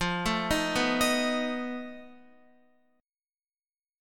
F7b5 chord